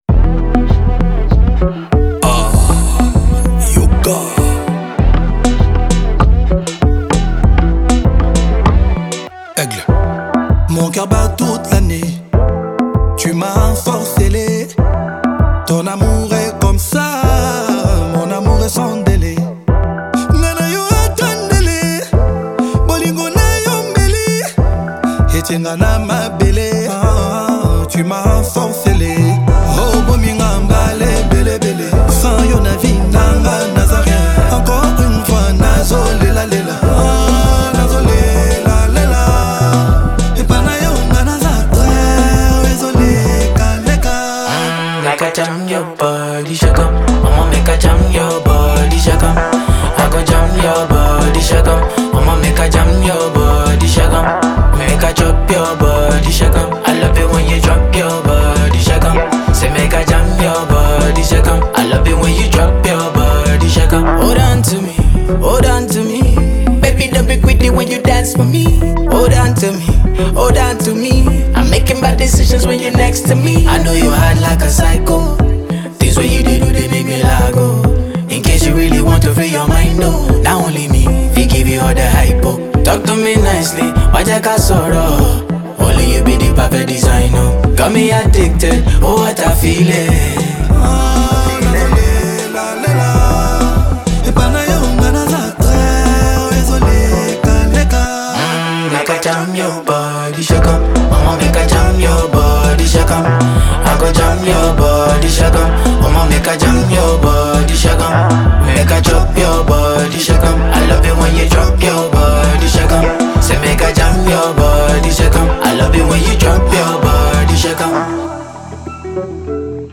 Congolese rumba
Afrobeats